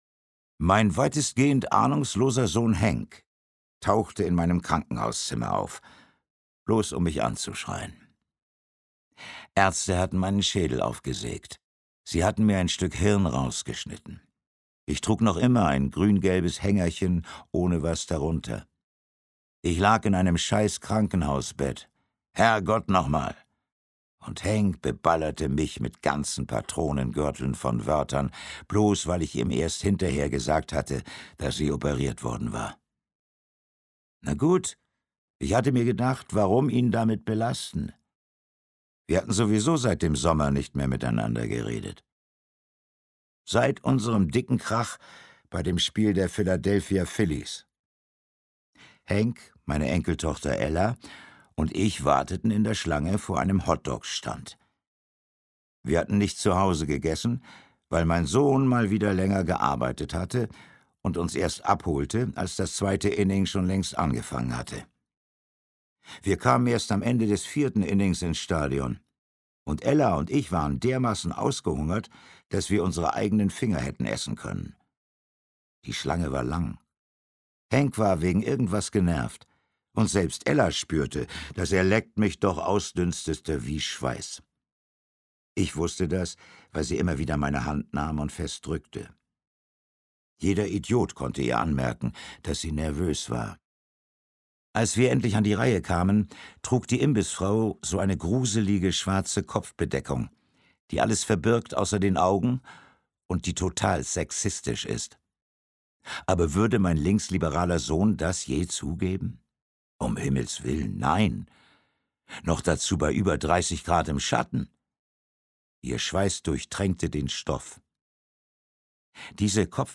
Volker Lechtenbrink (Sprecher)